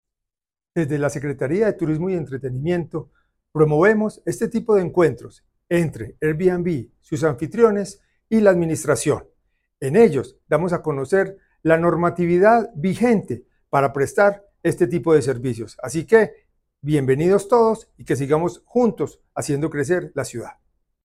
Palabras de José Alejandro González, secretario de Turismo y Entretenimiento